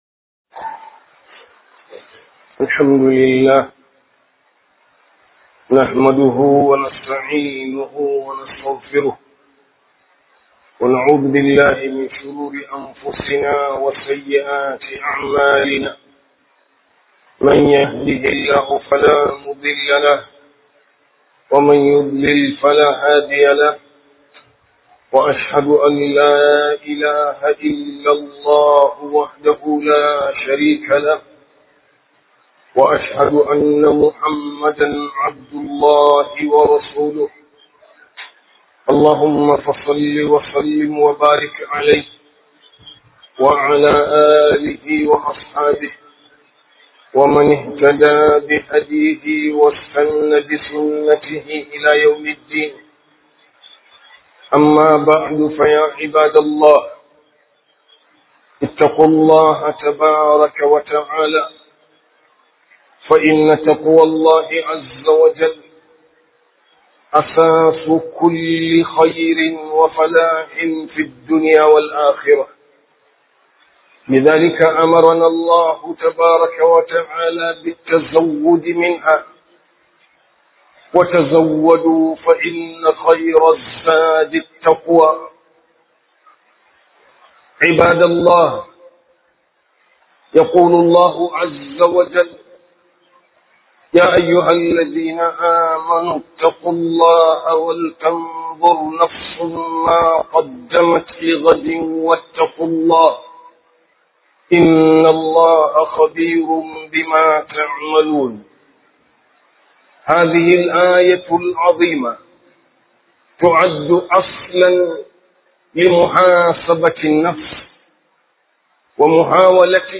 Yiwa Kai Hisabi - Huduba